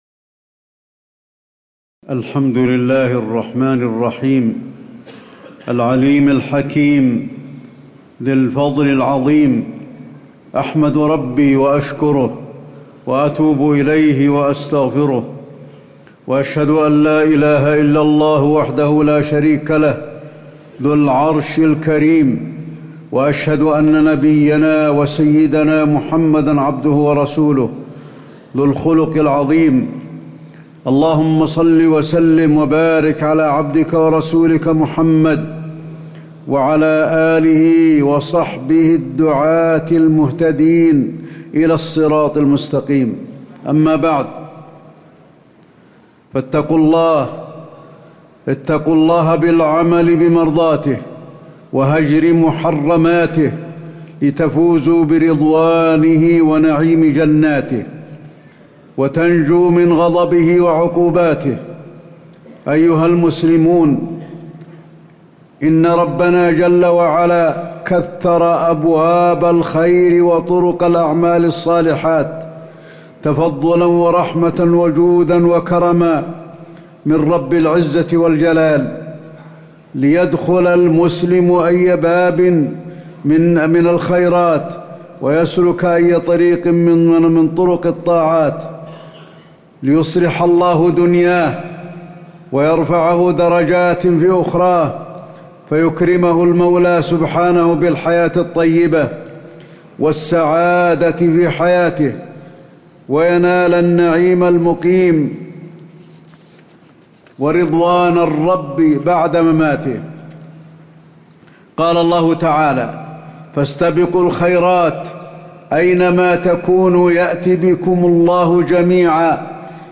تاريخ النشر ٣ رجب ١٤٣٨ هـ المكان: المسجد النبوي الشيخ: فضيلة الشيخ د. علي بن عبدالرحمن الحذيفي فضيلة الشيخ د. علي بن عبدالرحمن الحذيفي أبواب الخير وطرق الأعمال الصالحات